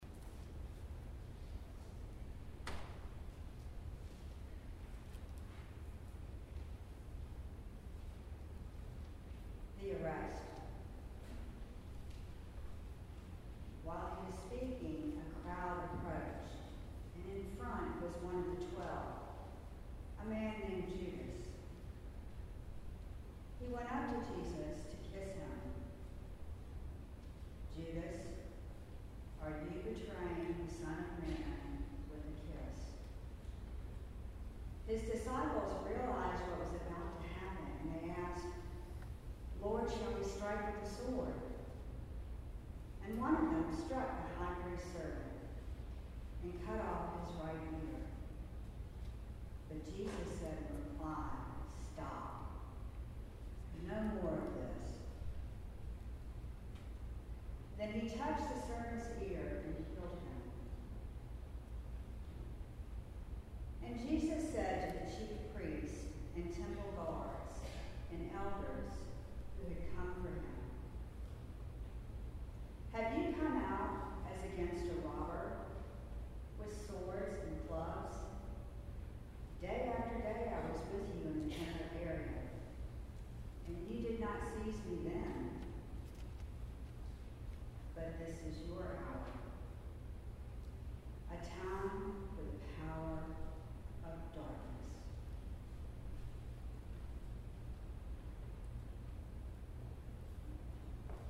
The St. William choir presented a Tenebrae Service on Palm Sunday 2015.
Reading "The Arrest"